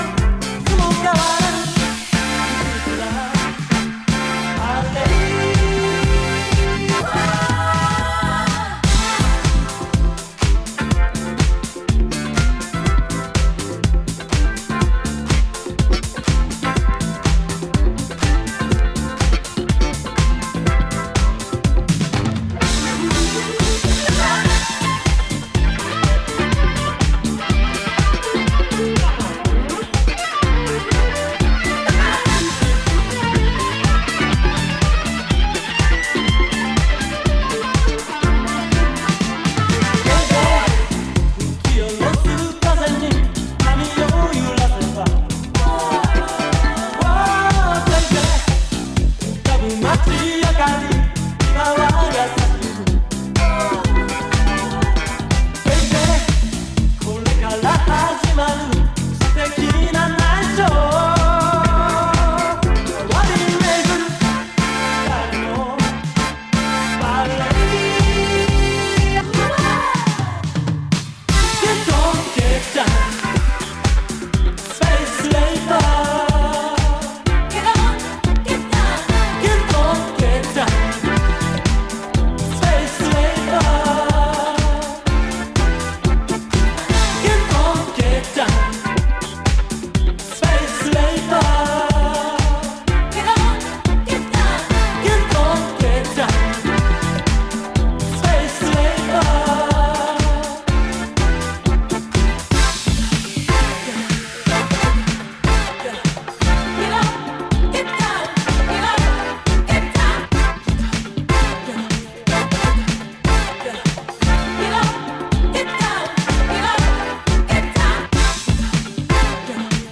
例に漏れず、全曲DJユースで強力なリエディット集となっています。